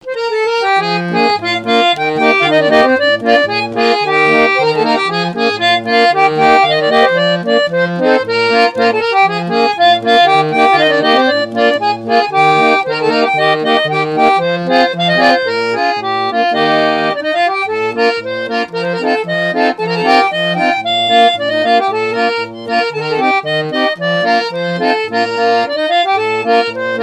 au moulin de la Chaussée, au Perrier.
danse : polka
musique mécanique